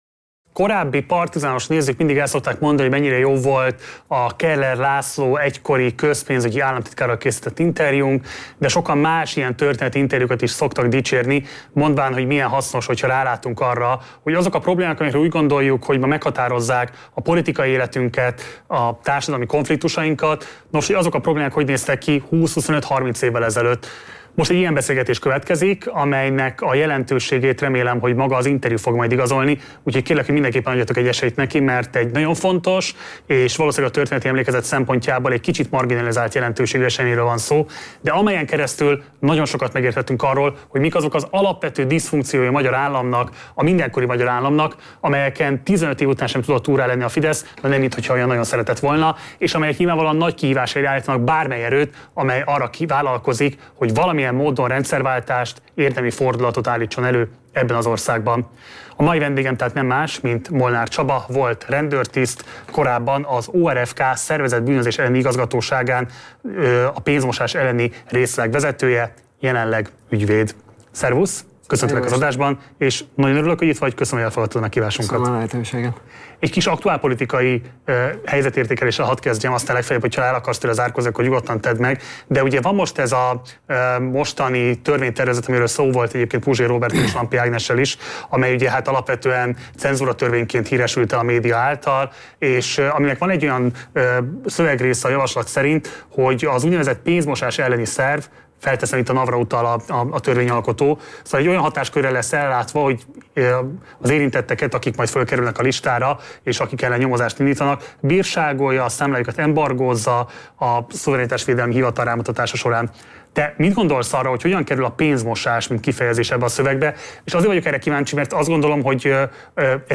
Interjúnkból ez mind kiderül!